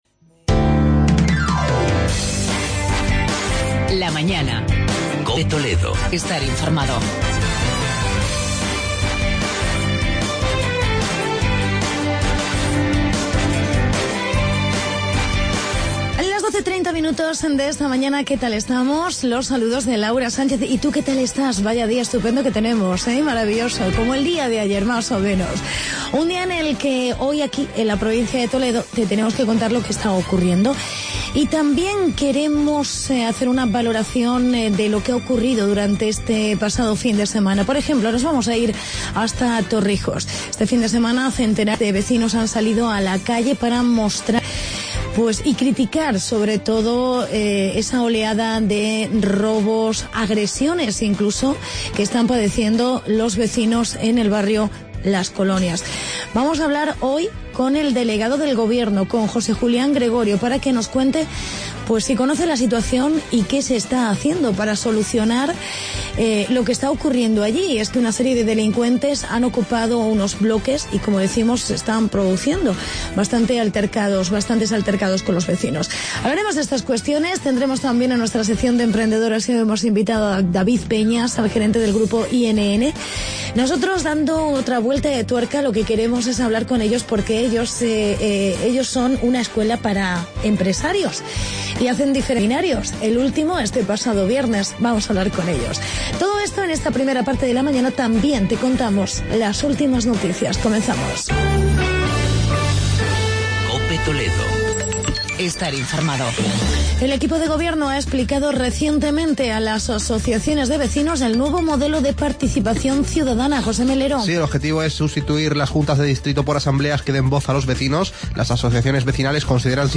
Entrevista con el delegado del gobierno, José Julián Gregorio sobre la oleada de delitos en Torrijos y la manifestación de los vecinos.